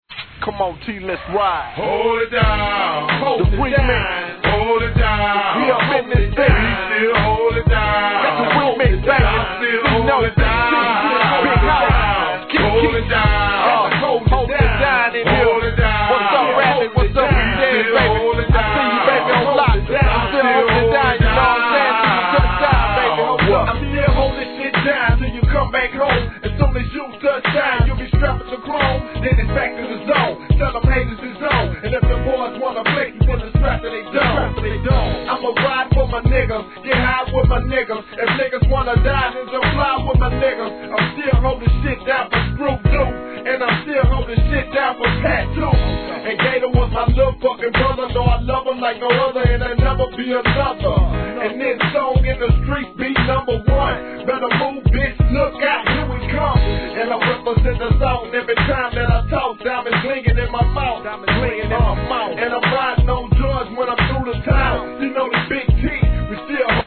G-RAP/WEST COAST/SOUTH
王道の哀愁メロ〜傑作!!